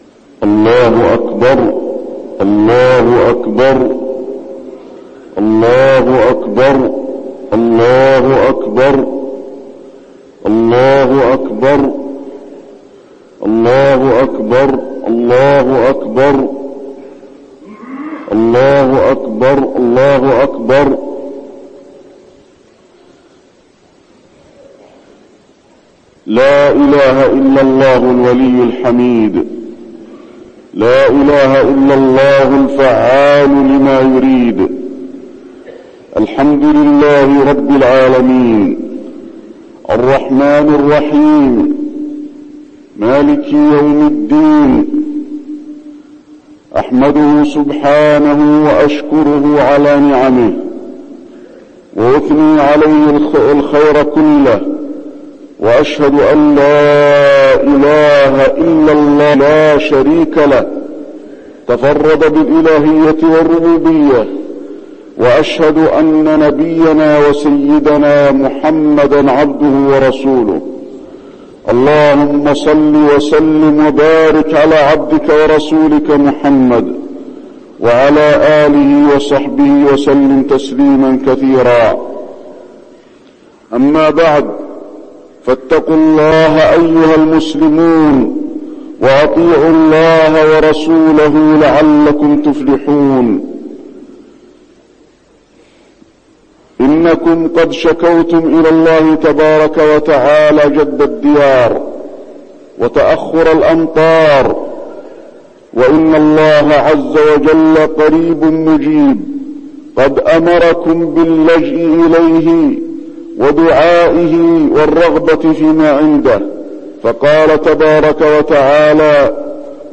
خطبة الاستسقاء - المدينة- الشيخ علي الحذيفي
المكان: المسجد النبوي